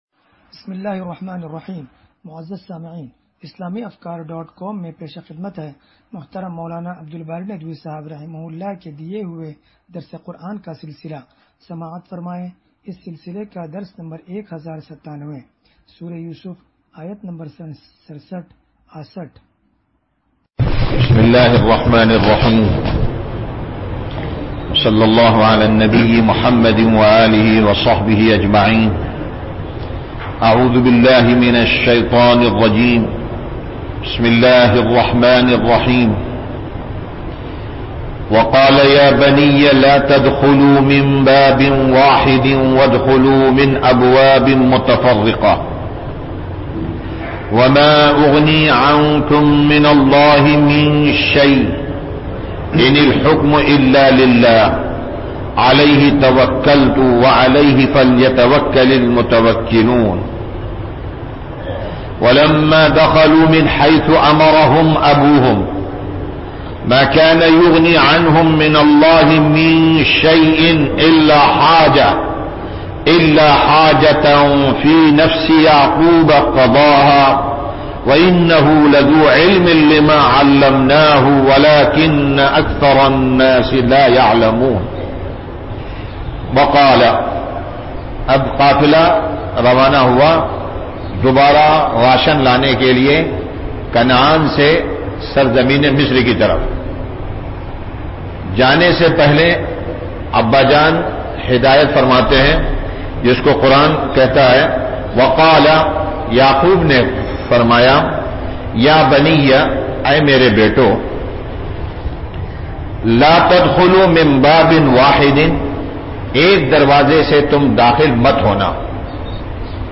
درس قرآن نمبر 1097